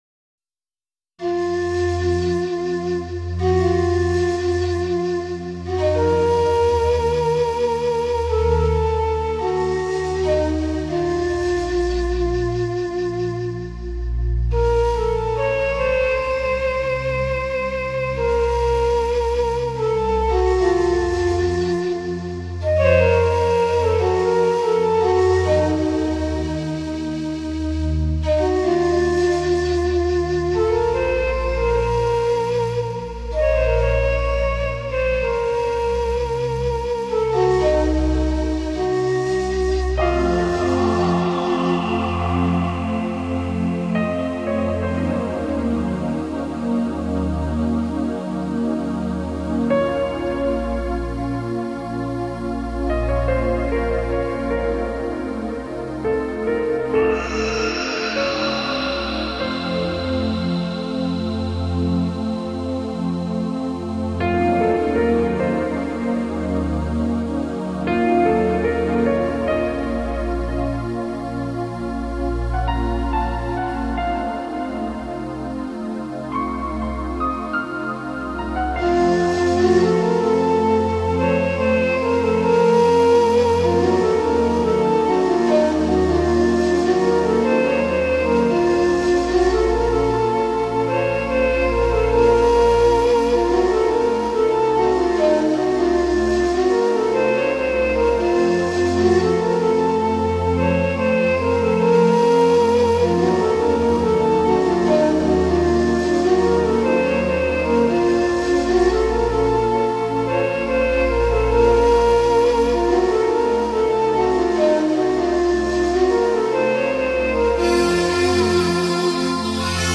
阿拉伯音乐的特殊节奏与音调再加上乐器
组合，音乐的色彩十分艳丽，从第一首乐曲开始就显得热闹非凡，透过音乐